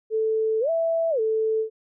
To make the patch: Make an oscillator with a line~ object to control its amplitude.
Make two more message boxes to cause the frequency to slide to each of 660 and 440.